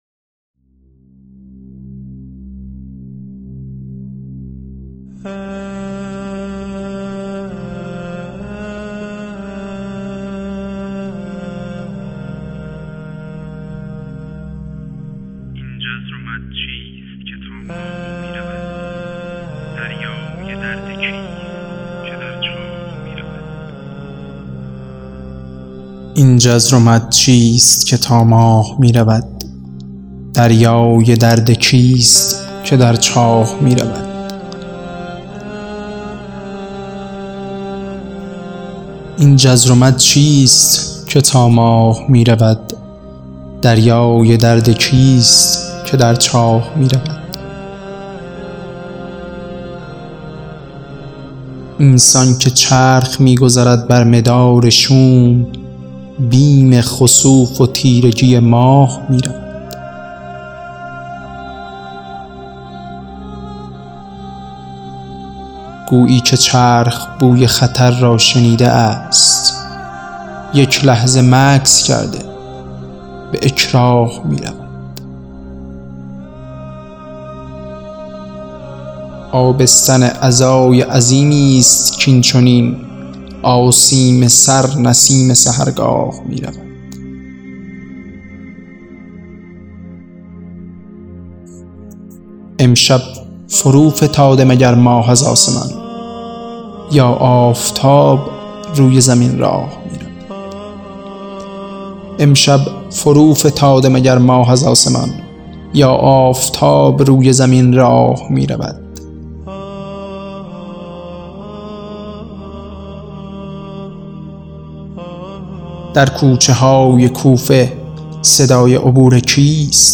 • دکلمه حضرت علی, دکلمه صدای عبور